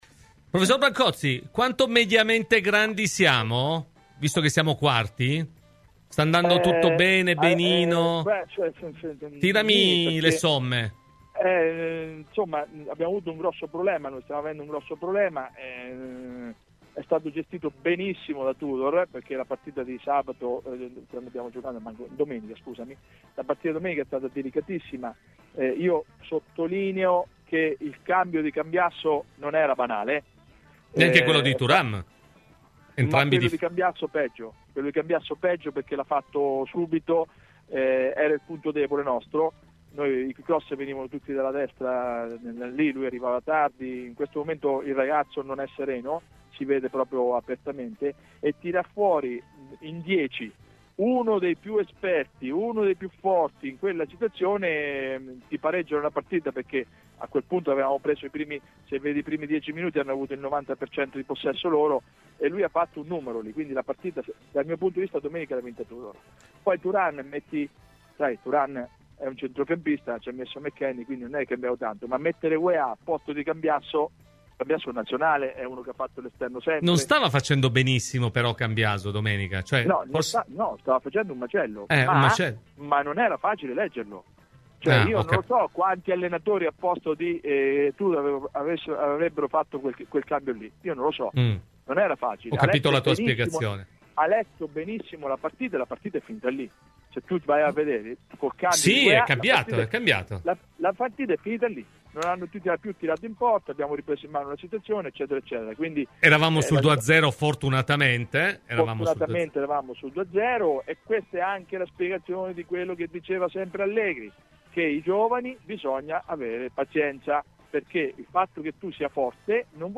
Ospite di "Cose di Calcio" su Radio Bianconera